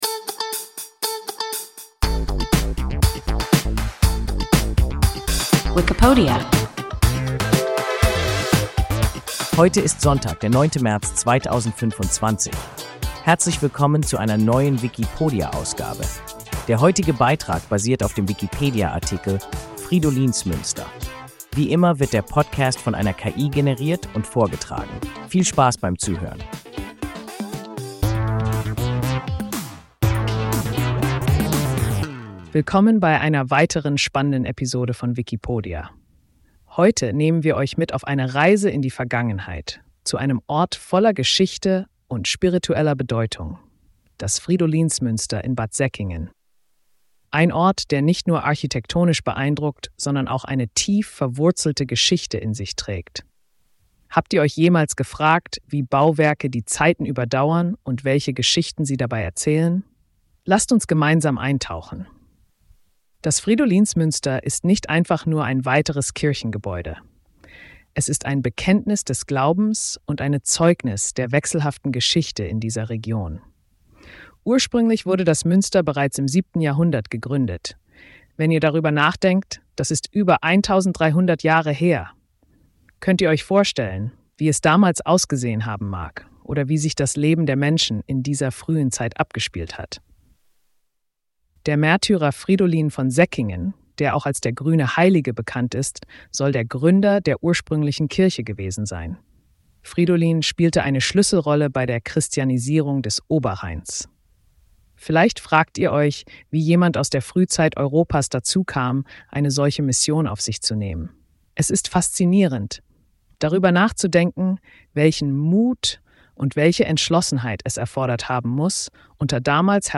Fridolinsmünster – WIKIPODIA – ein KI Podcast